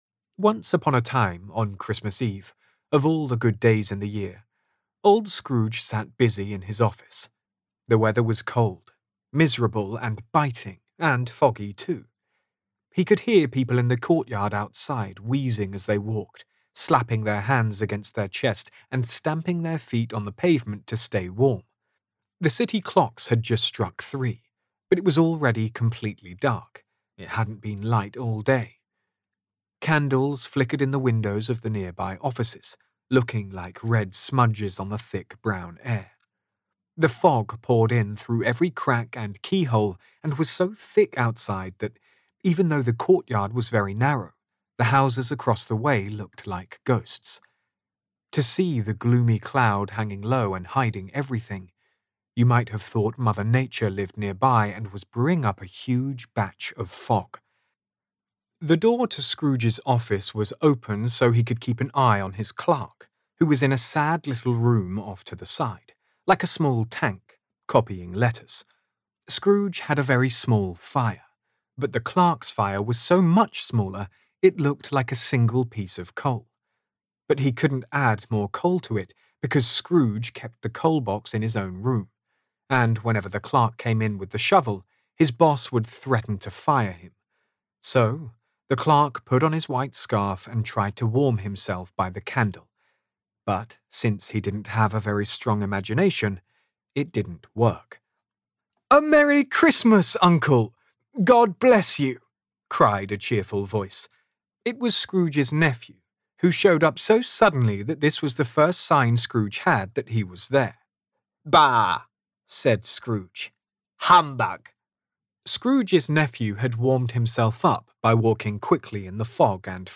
Audio Book Voice Over Narrators
Yng Adult (18-29) | Adult (30-50)